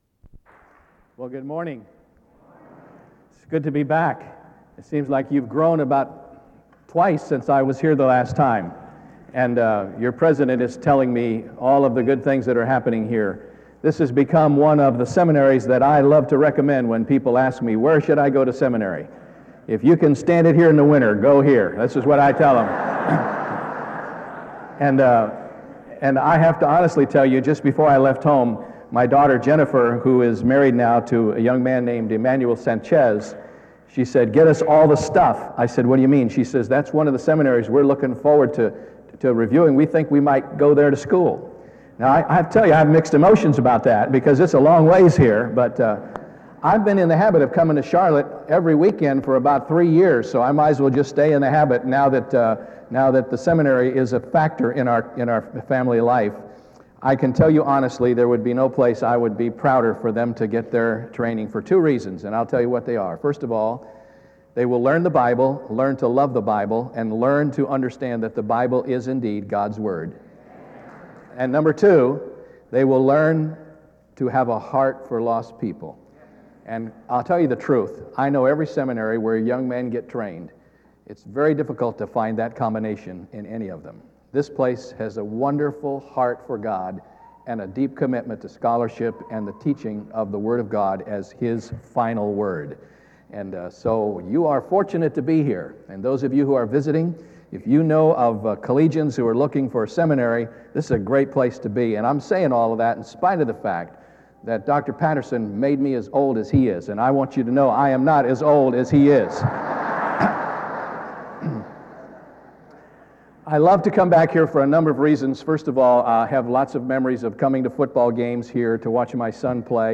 SEBTS Chapel - David Jeremiah May 3, 2001
In Collection: SEBTS Chapel and Special Event Recordings - 2000s Miniaturansicht Titel Hochladedatum Sichtbarkeit Aktionen SEBTS_Chapel_David_Jeremiah_2001-05-03.wav 2026-02-12 Herunterladen